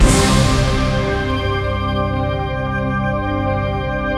EMPEROR SY99 1.wav